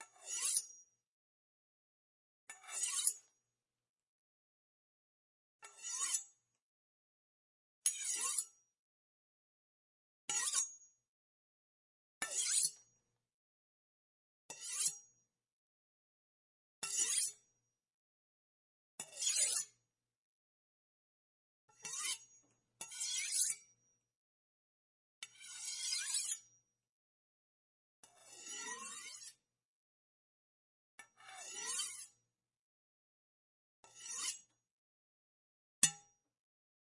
描述：metal knife scrape against sharpener. Stereo Recording, Recorded with a Zaxcom Deva II, and Sennheiser MKH 30 and MKH 40 as midside, decoded to AB stereo.
标签： household indoor knife metal scrape